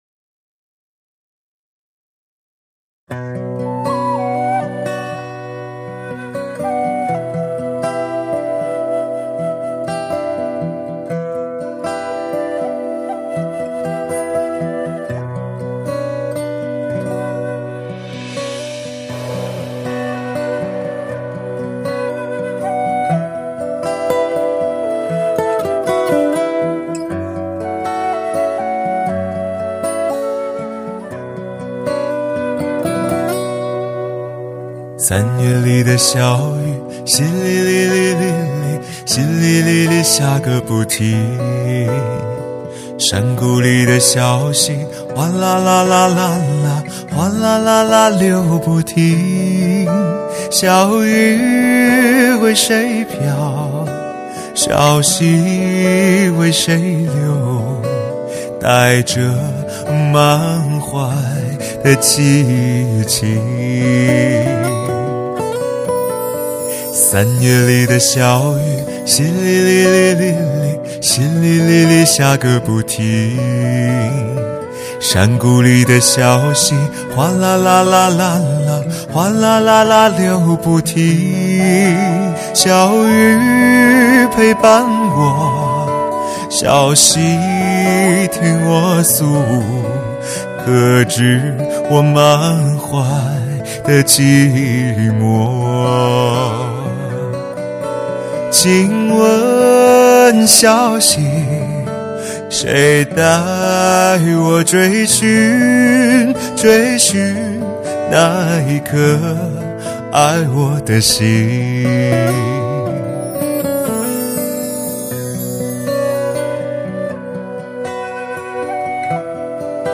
发烧碟唱片